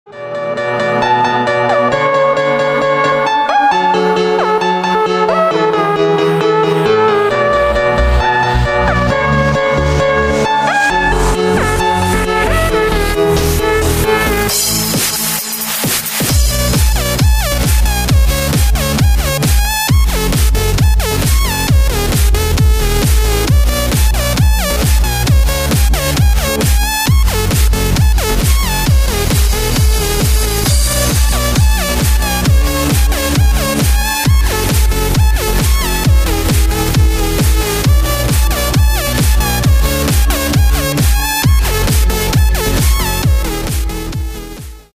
• Качество: 128, Stereo
громкие
dance
Electronic
EDM
электронная музыка
без слов
клавишные
энергичные
electro house
пищалка